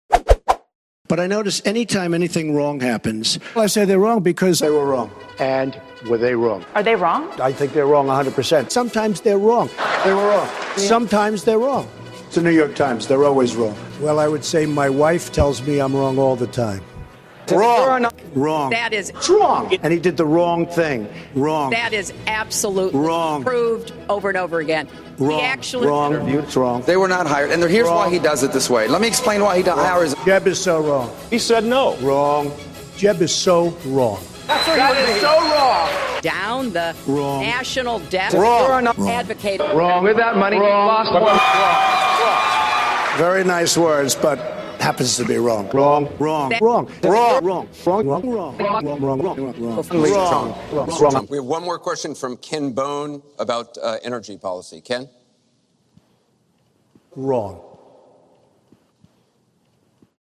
Trump Saying 'WRONG!' Supercut.mp3